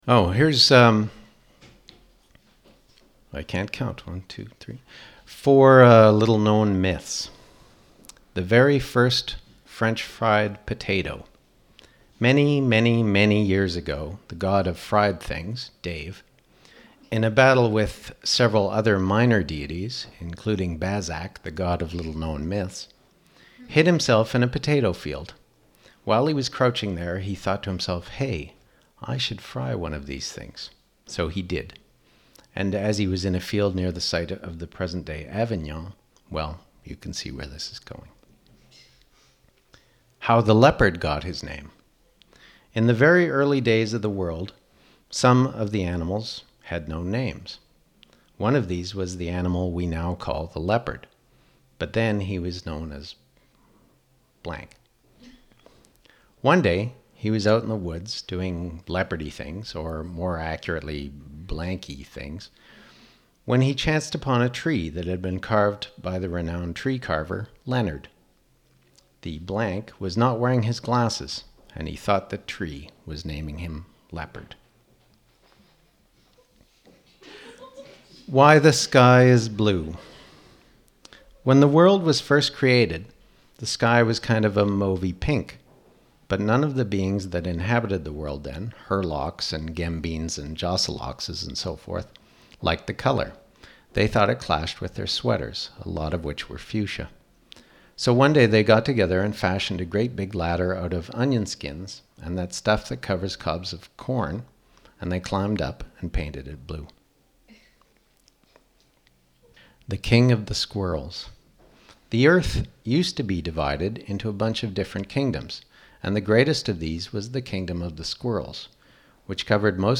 Why It's A Bunch of Audio Stories